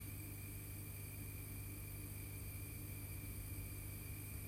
今回は防音設備が整った音楽スタジオで、アロマディフューザー３機種と加湿器の音量を実際に計測・録音しました。
FUWARI10B37.5db36db
※iphone15で各機器から50cm離して録音しました。
音声だけ聞くと、FUWARI10BとFUWARI20Bでは大きな差はありません。